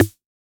RDM_Raw_MT40-Snr03.wav